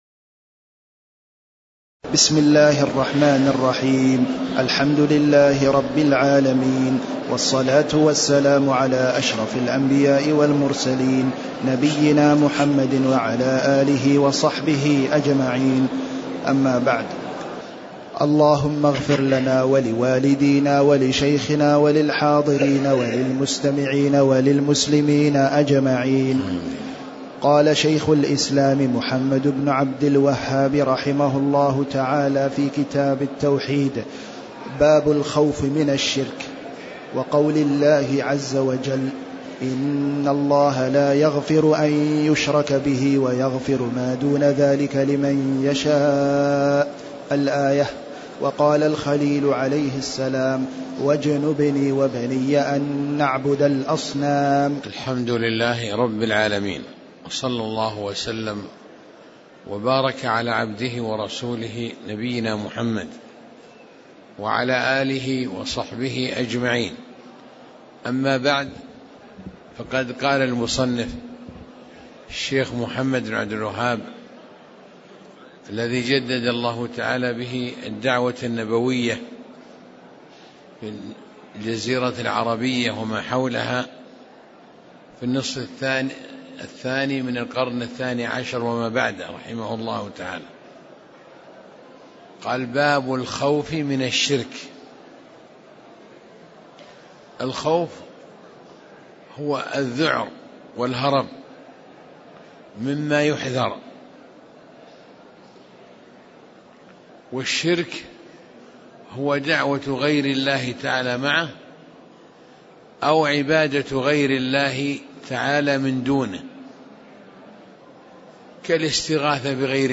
تاريخ النشر ٤ رجب ١٤٣٨ هـ المكان: المسجد النبوي الشيخ